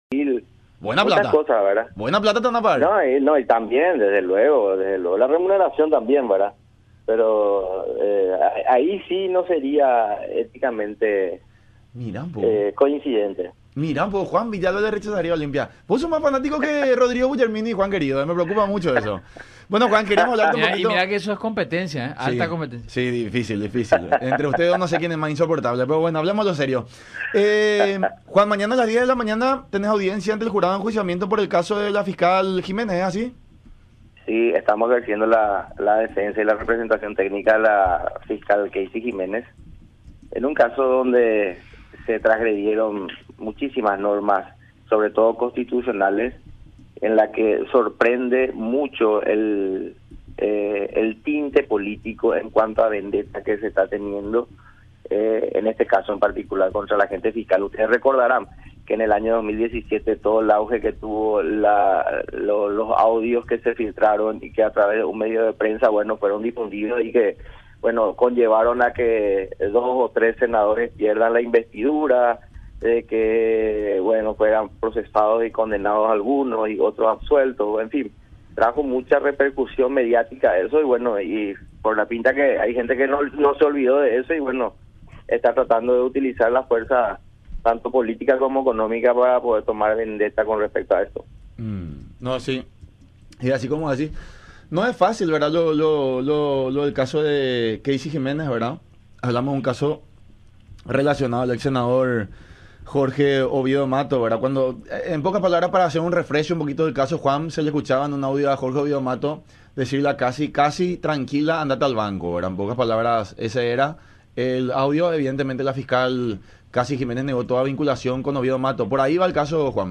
en el programa “La Unión Hace La Fuerza” por Unión TV y radio La Unión.